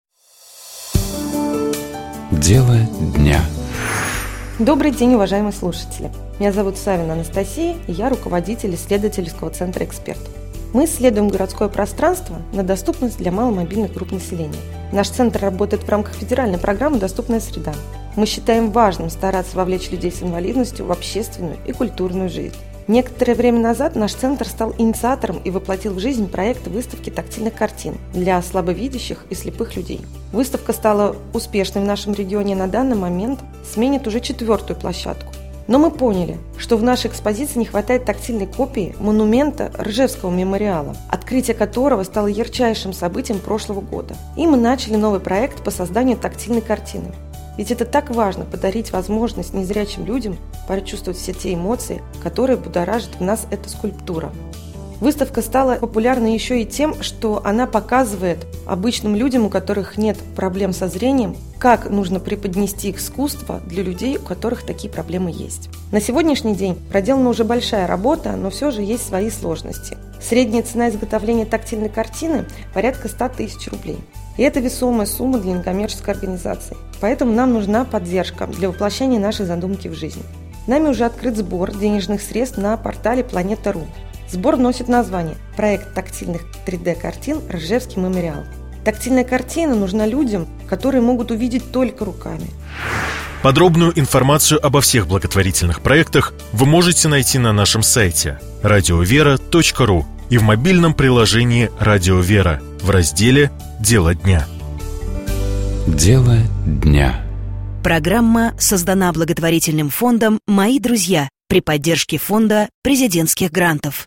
Комментирует епископ Переславский и Угличский Феоктист.